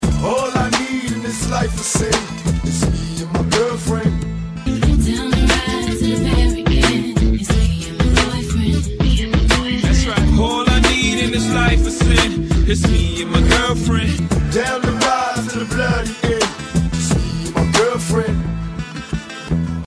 • Category RnB